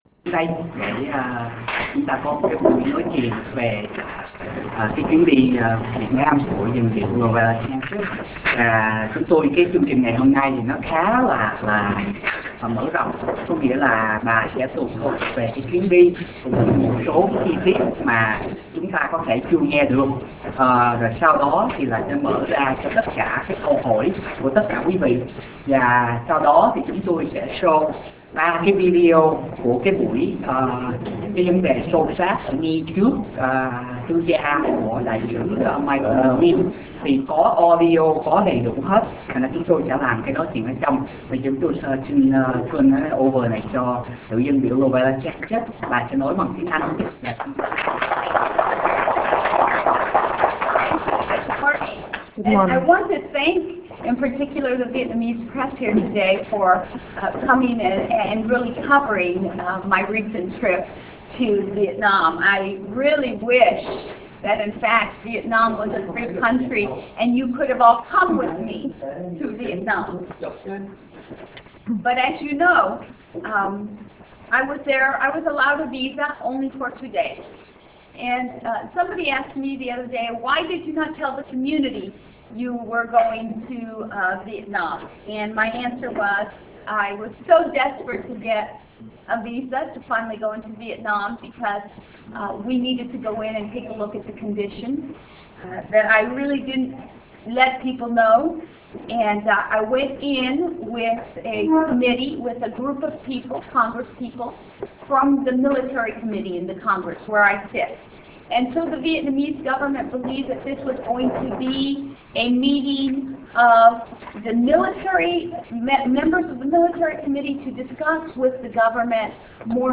B� LORETTA SANCHEZ HỌP B�O TẠI ORANGE COUNTY SAU KHI TỪ VIỆT NAM VỀ LẠI HOA KỲ